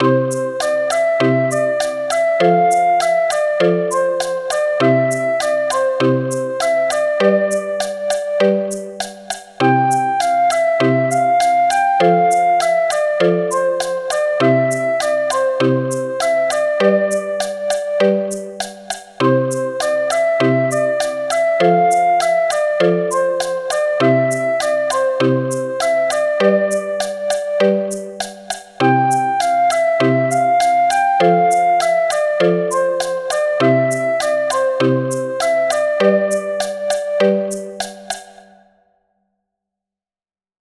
• Frauta doce
• Tubos sonoros (ou xilófono contralto no seu defecto)
• Xilófono baixo
• Axóuxeres
• Maracas
• Temple blocks (ou caixa chinesa)